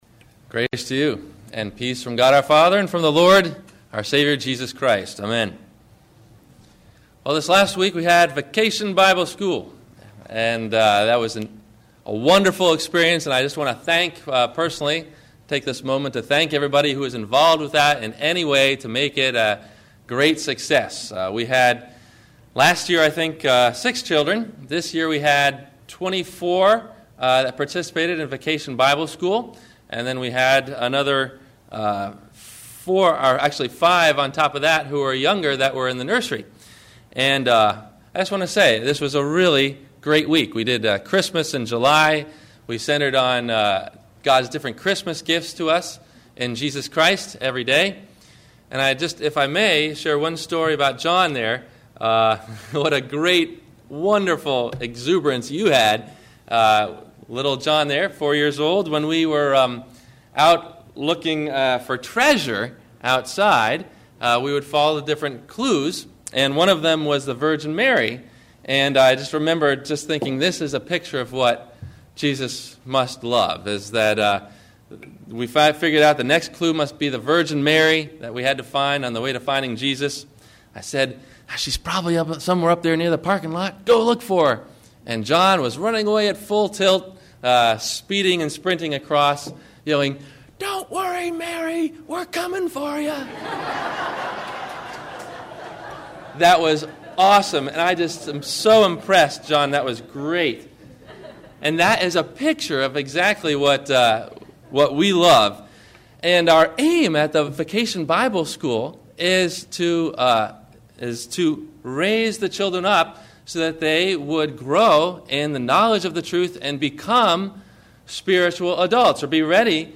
Grow Up and Be a Child – Sermon – July 27 2008
Listen for these questions and answers about “Grow Up and Be a Child”, in the 1-part MP3 Audio Sermon below.